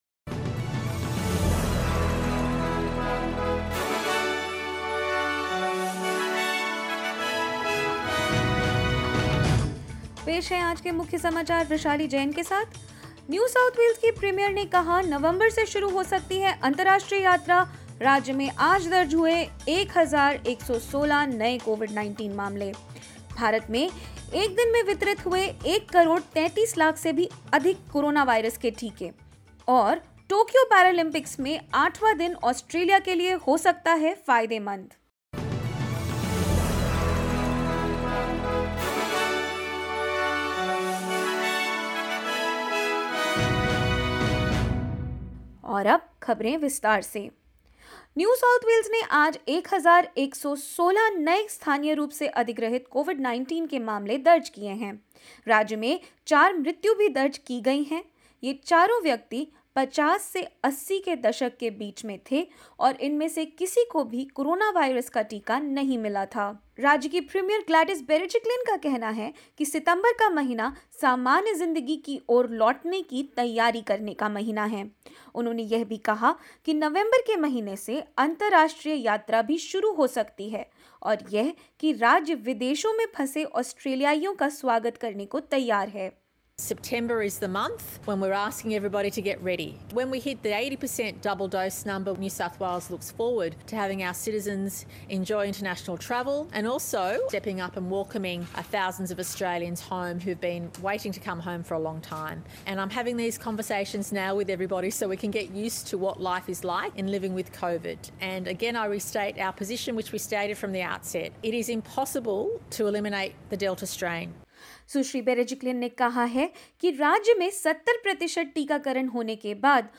0109_hindi_news.mp3